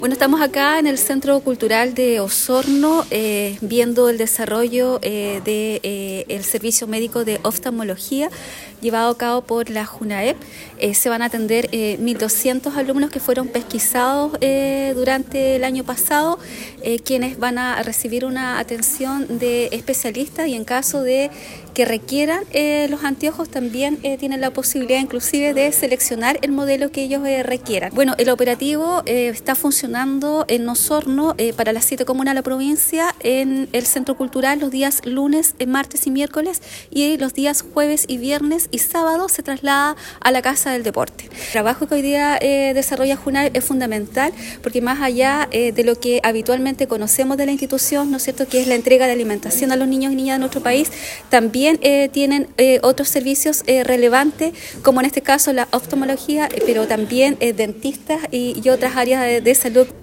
Este operativo se desarrollará hasta mañana en el centro cultural, en tanto el jueves, viernes y sábado se trasladará a la Casa del Deporte, como lo explicó la Delegada Presidencial Provincial Claudia Pailalef.